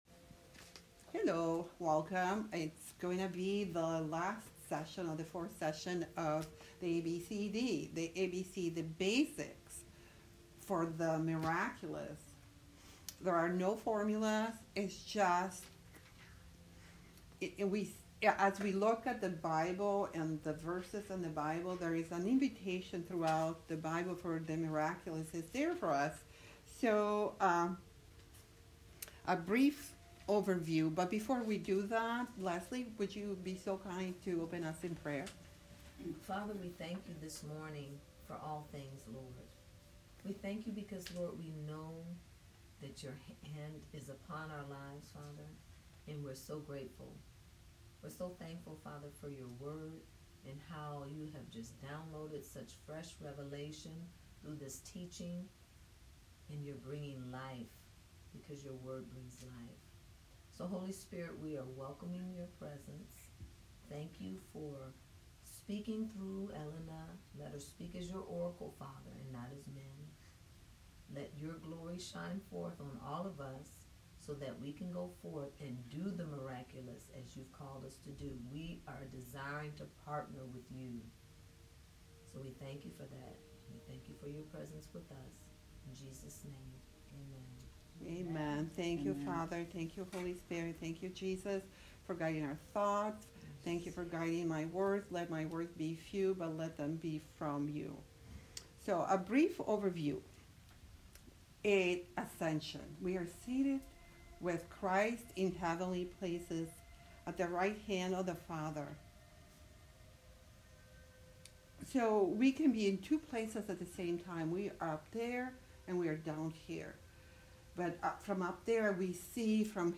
Adventures In The Miraculous Service Type: Class Join us for week 4 of Adventures In The Miraculous Sunday School.